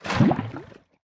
Minecraft Version Minecraft Version 1.21.4 Latest Release | Latest Snapshot 1.21.4 / assets / minecraft / sounds / item / bucket / empty_lava3.ogg Compare With Compare With Latest Release | Latest Snapshot
empty_lava3.ogg